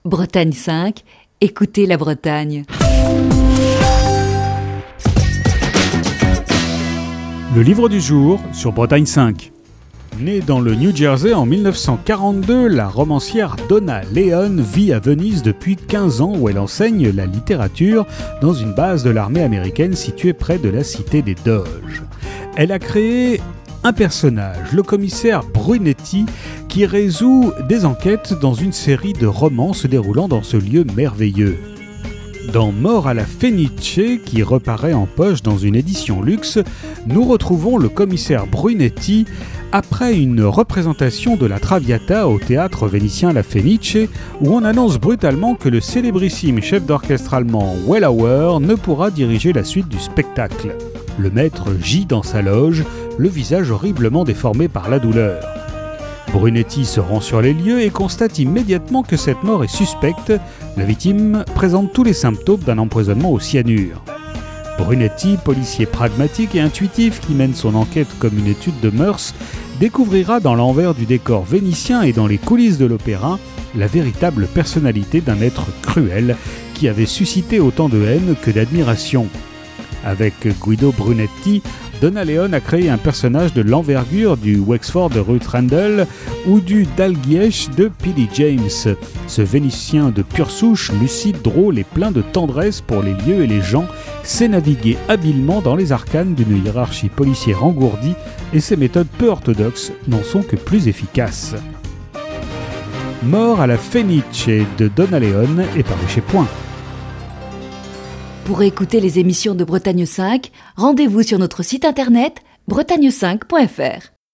Chronique du 16 janvier 2020.